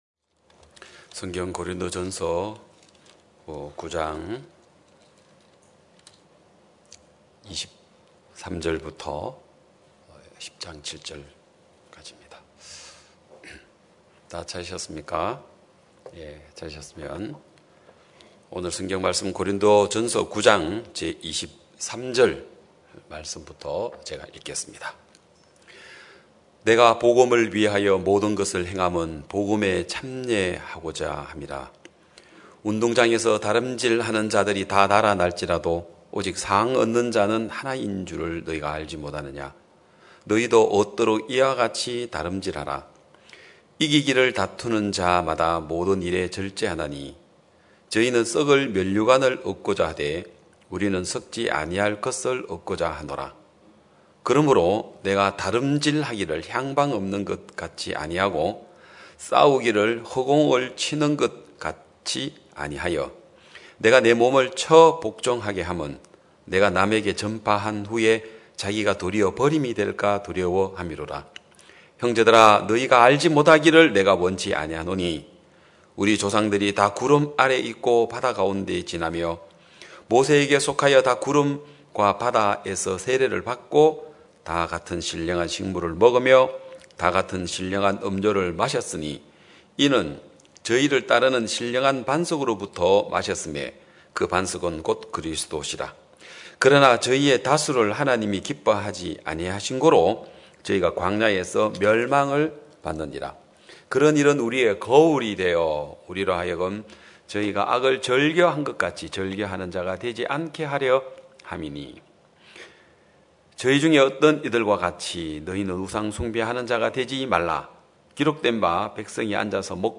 2021년 9월 26일 기쁜소식양천교회 주일오전예배
성도들이 모두 교회에 모여 말씀을 듣는 주일 예배의 설교는, 한 주간 우리 마음을 채웠던 생각을 내려두고 하나님의 말씀으로 가득 채우는 시간입니다.